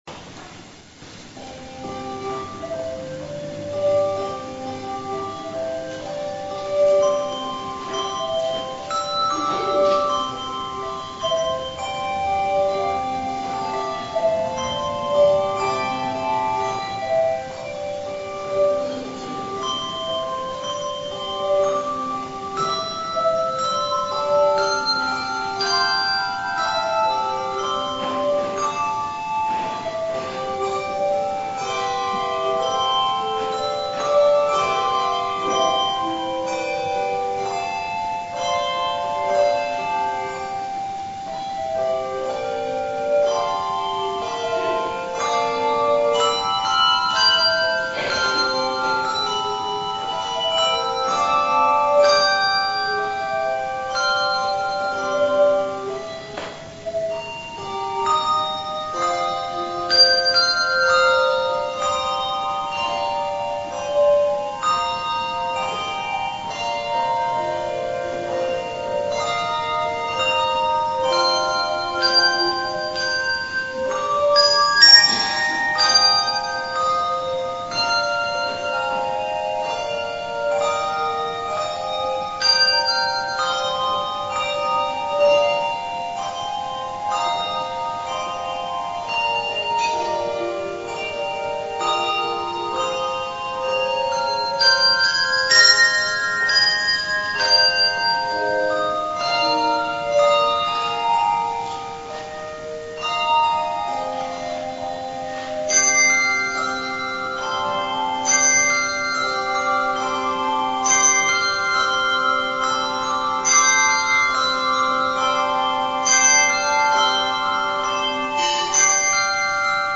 The Second Reformed Adult Bell Choir plays "Away in a Manger" arranged by Douglas Wagner
Handbell Music